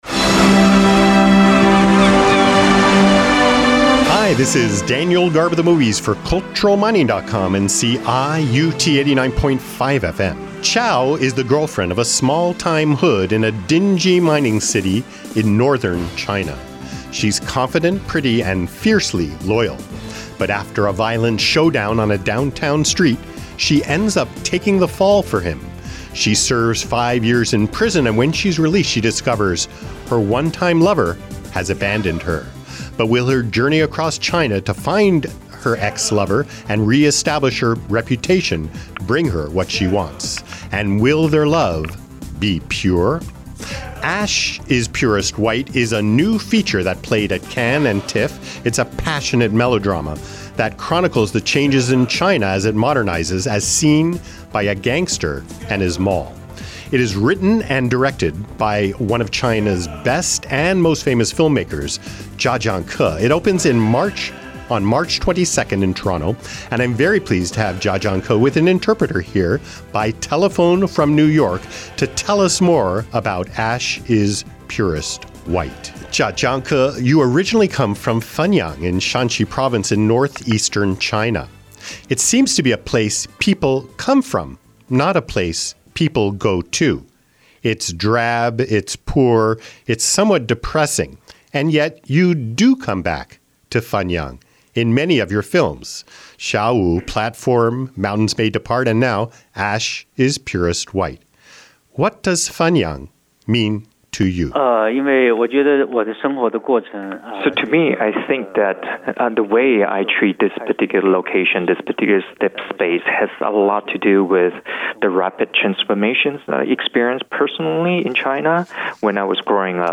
I spoke to Jia Zhang-ke in New York City via telephone from CIUT 89.5 FM in Toronto.